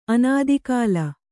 ♪ anādikāla